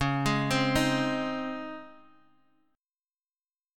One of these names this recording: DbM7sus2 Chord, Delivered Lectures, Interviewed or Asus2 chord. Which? DbM7sus2 Chord